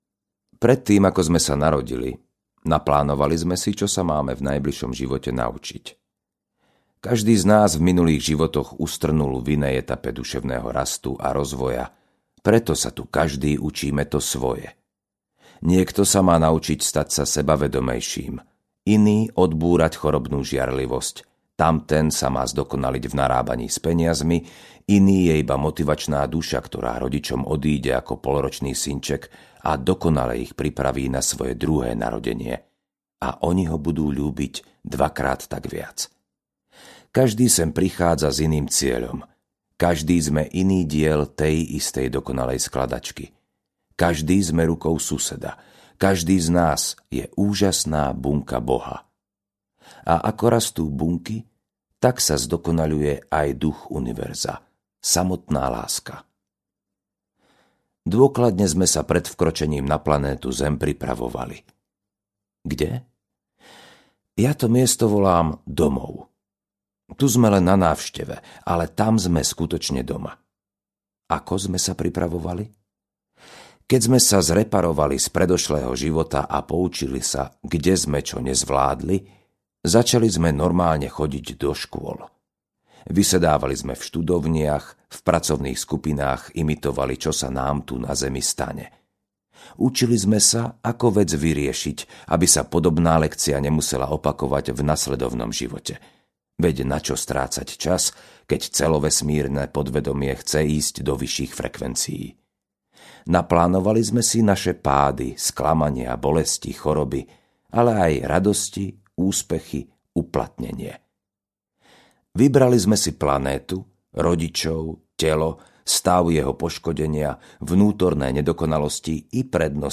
Šlabikár šťastia 1 - Návrat k sebe audiokniha
Ukázka z knihy